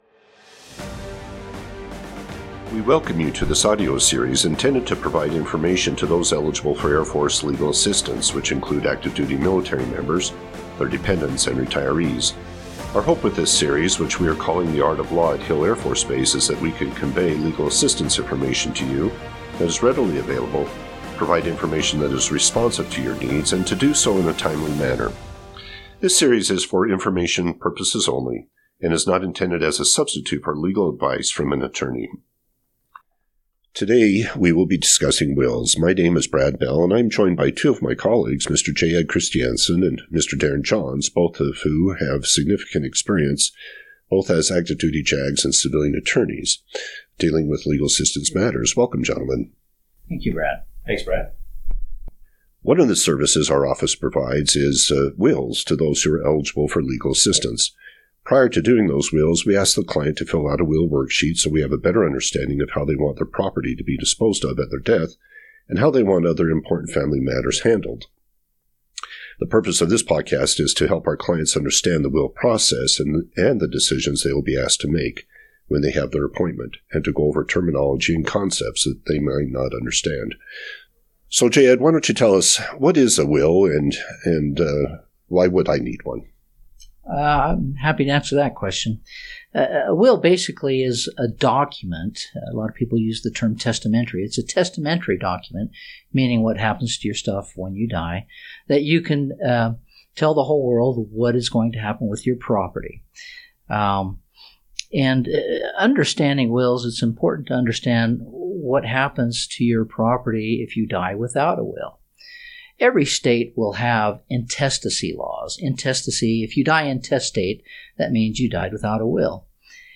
In the episode two JAG attorneys discuss the will process and the decisions legal assistance eligible clients will need to make as they create their will worksheet. The will worksheet is necessary prior to having an appointment with an attorney.